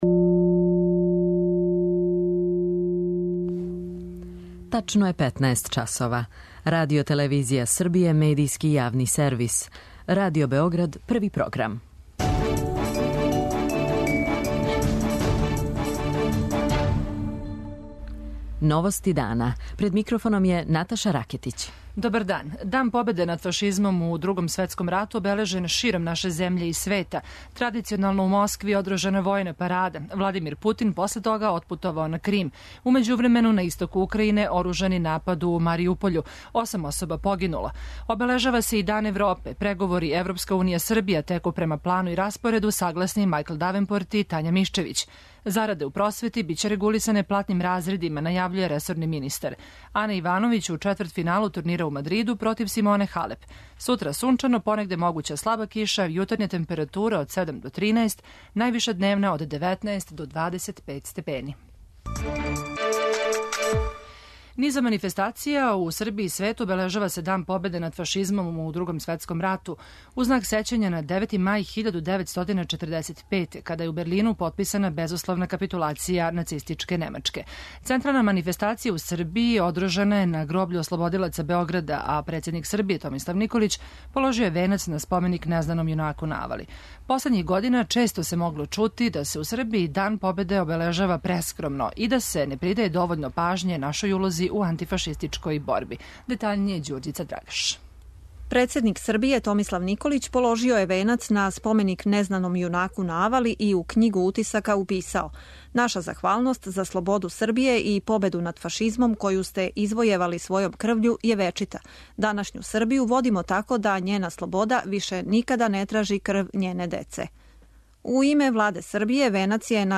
У емисији ћете чути репортажу коју смо забележили на лицу места.